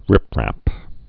(rĭprăp)